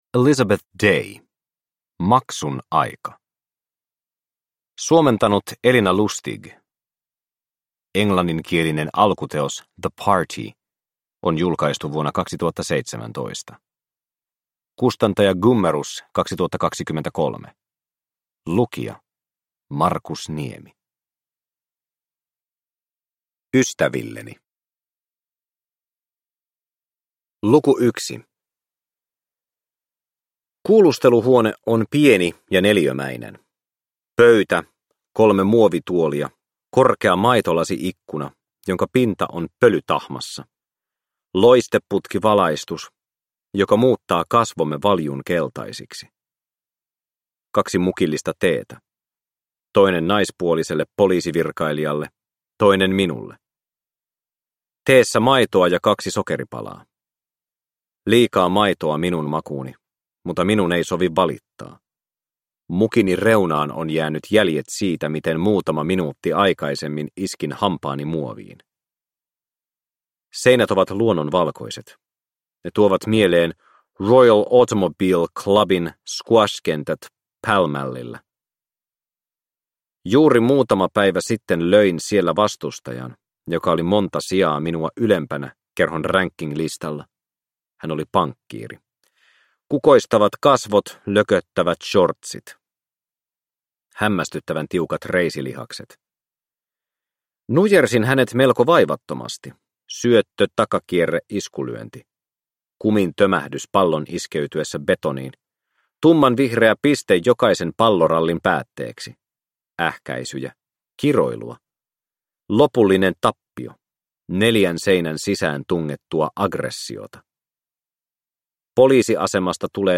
Maksun aika – Ljudbok – Laddas ner